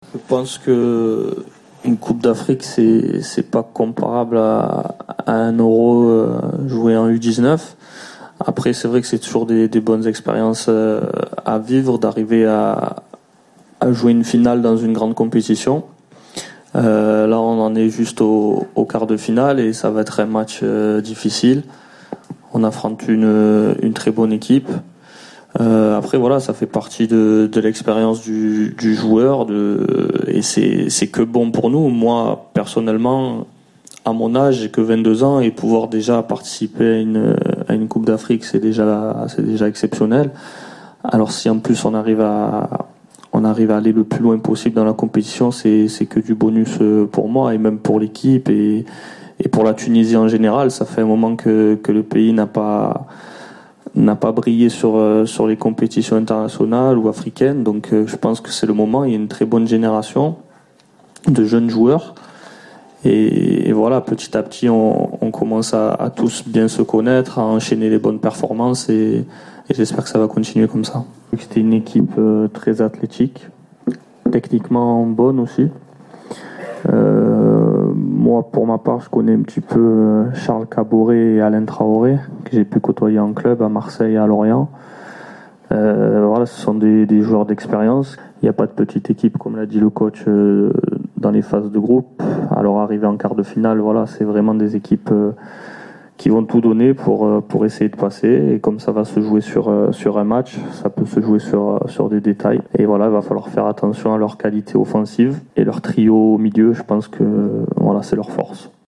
تحدث المدرب الوطني هنري كسبرجاك خلال الندوة الصحفية حول مقابلة يوم غد أمام بوركينا فاسو في إطار الدور ربع النهائي لكأس إفريقيا 2017 .